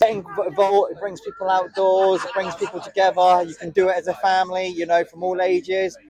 motocross-3.mp3